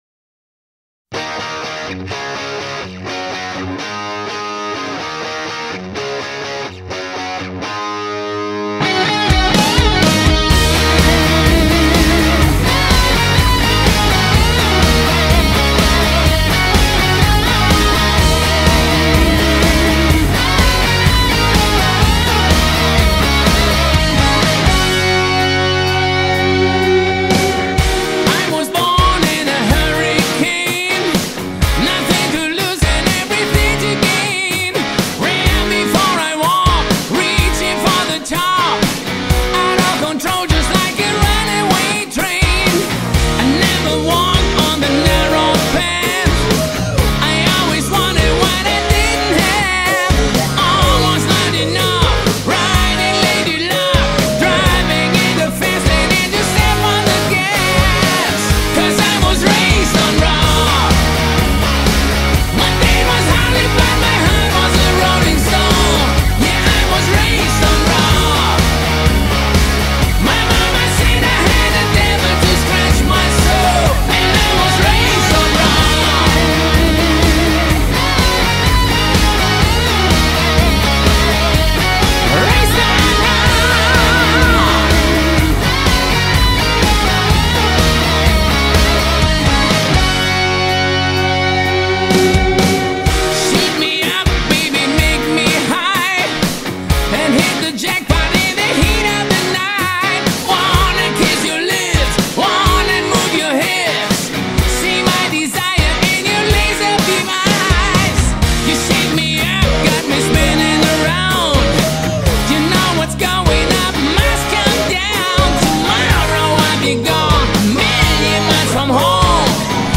Hard Rock, Heavy Metal